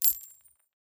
coin_tiny.wav